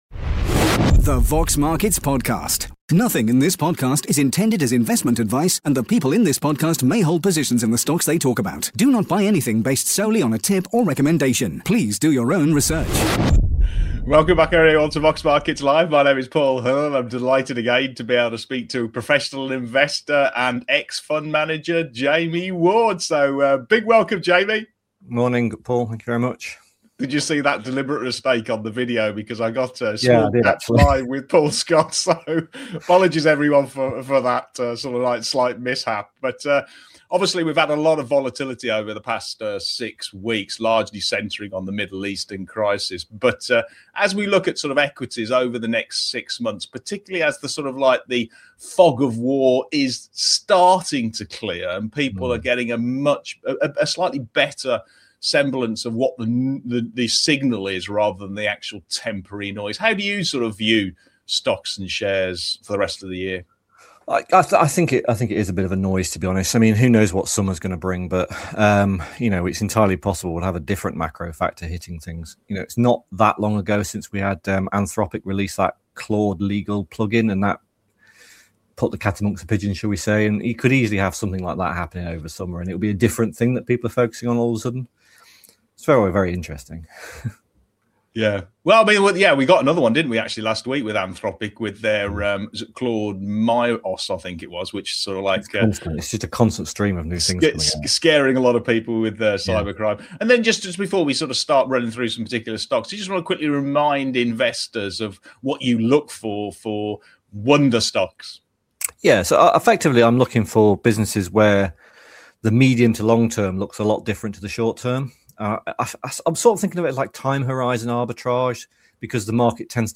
In this week’s live Exchange